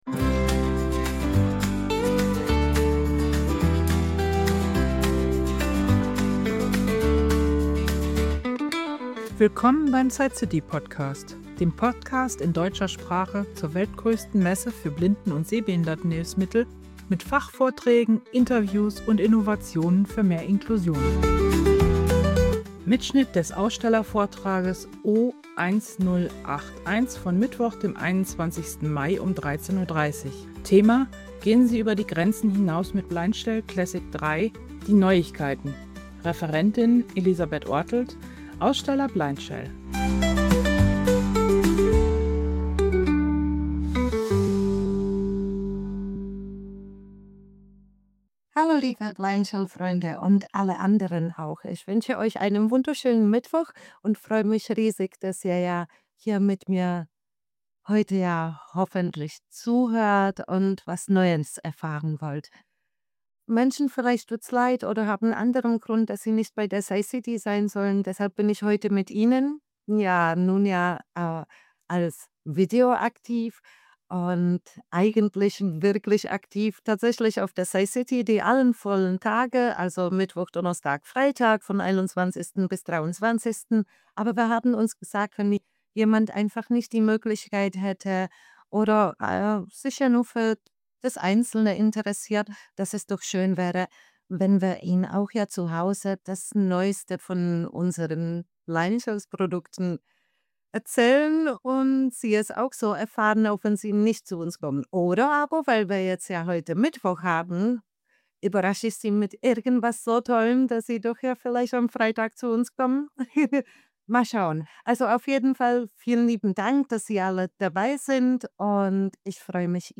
Die Präsentation wurde während der SightCity 2025 am 21. Mai als Ausstellervortrag O1081 aufgezeichnet und bietet einen umfassenden Einblick in die neuesten Entwicklungen der barrierefreien Mobiltelefonie.